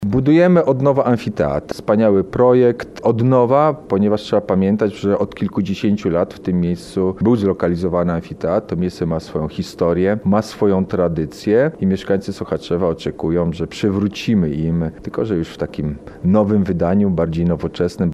– W Sochaczewie pieniądze pozwolą na modernizację miejskiego amfiteatru – zaznacza burmistrz Piotr Osiecki.